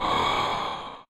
burp.ogg